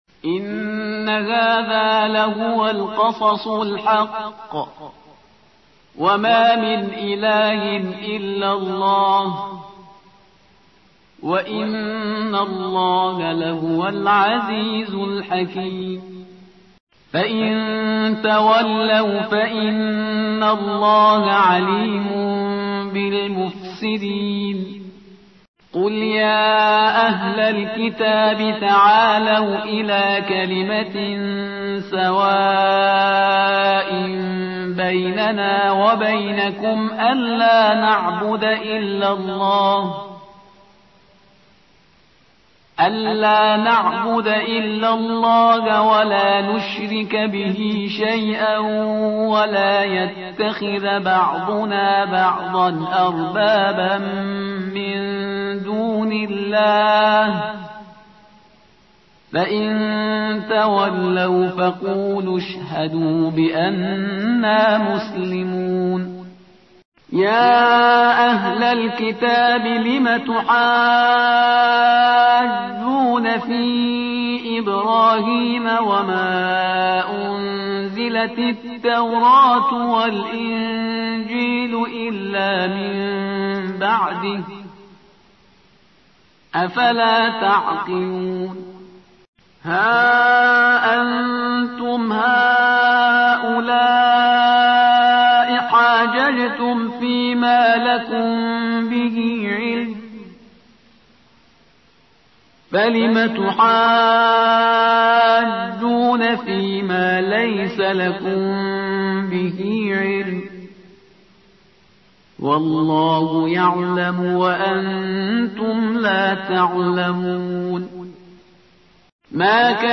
ترتیل سوره(آل عمران)